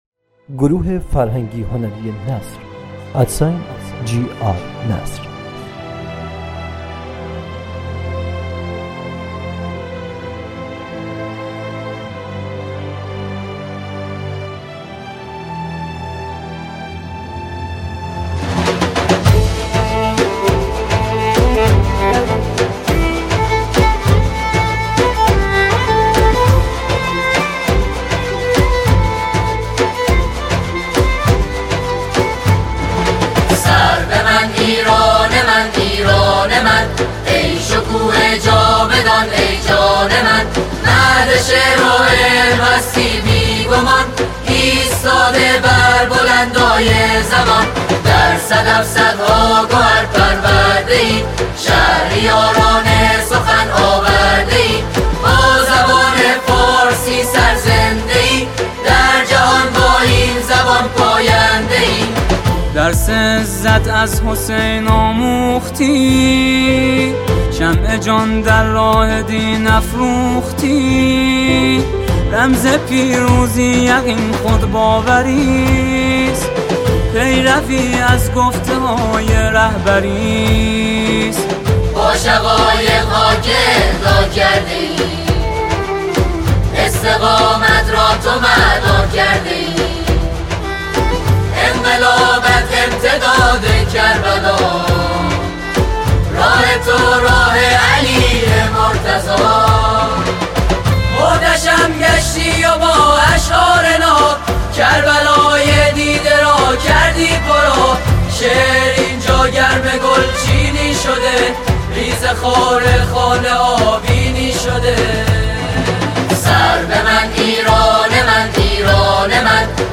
اثری است سرشار از شور ملی، افتخار فرهنگی و عشق به ایران
ژانر: سرود